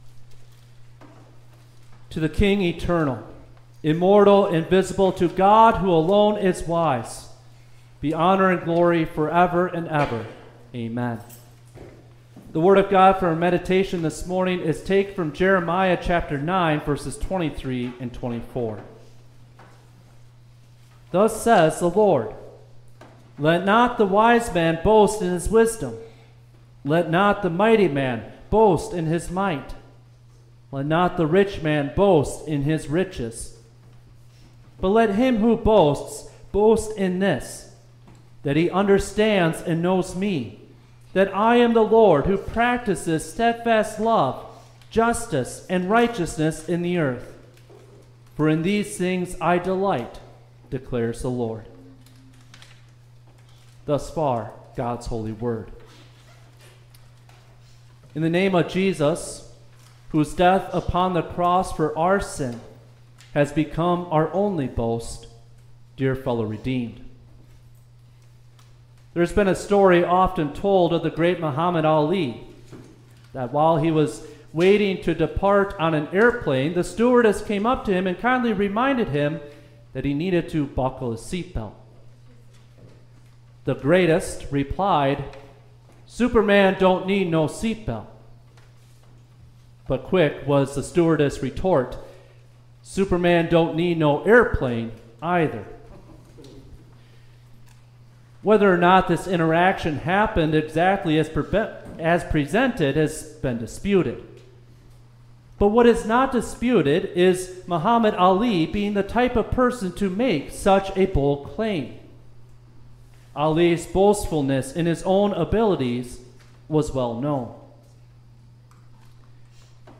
Septuagesima-Service-_-February-1-2026_.mp3